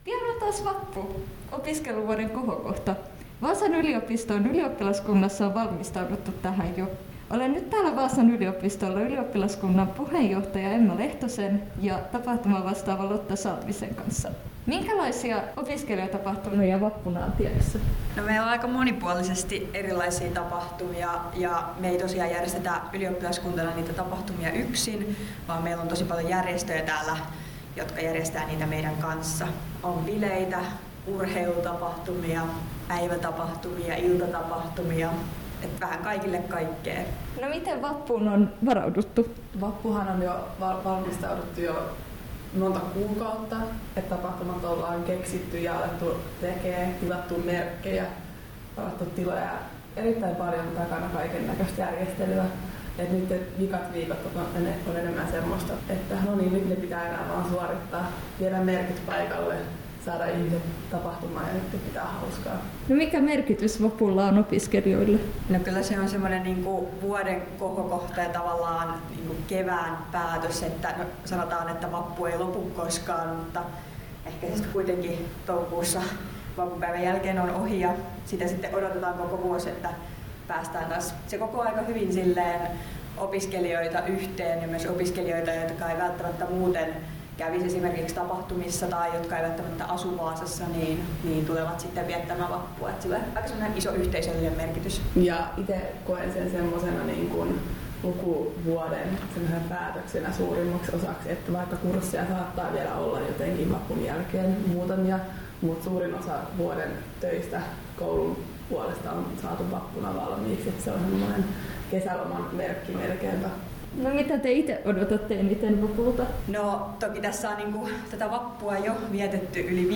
Åbo Akademis Studentkår och Vaasan yliopiston ylioppilaskunnan intervjuas